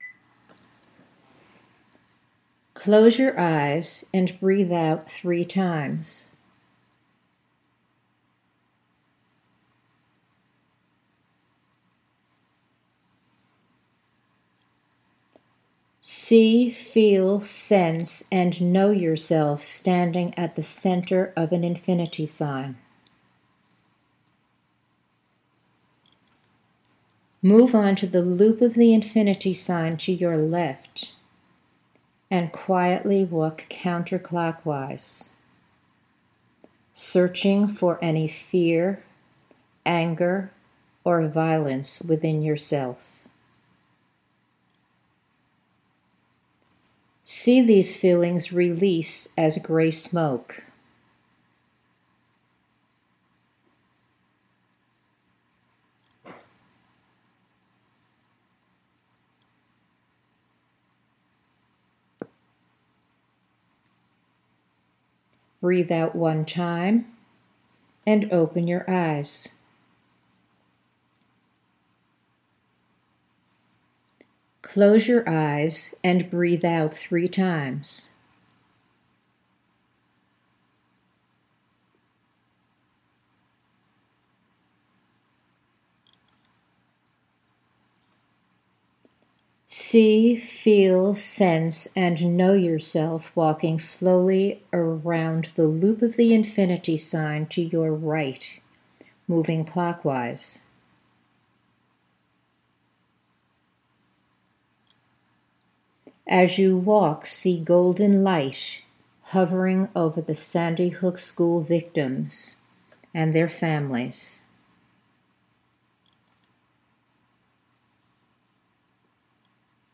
It is completed when you hear the tone.